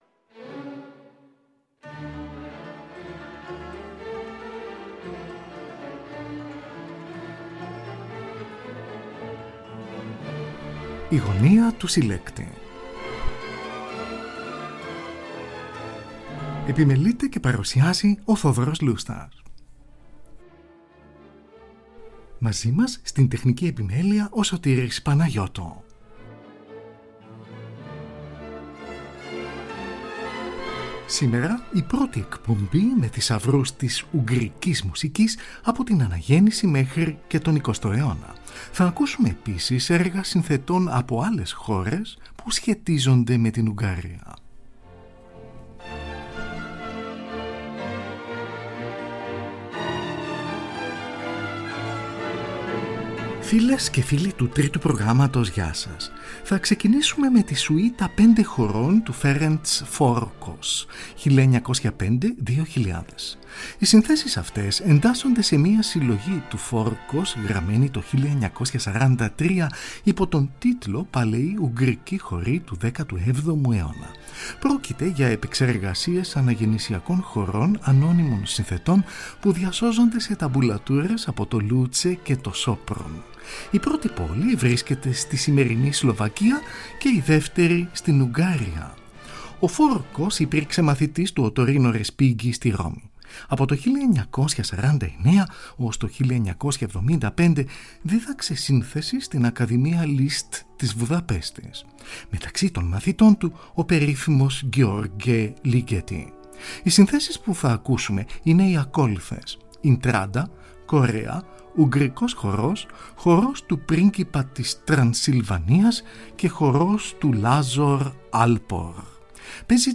Aκούγονται συνθέσεις των Ferenc Farkas, Bálint Bakfark, József Bengraf, Ferenc Erkel, καθώς και έργα συνθετών από άλλες χώρες, που σχετίζονται με την Ουγγαρία.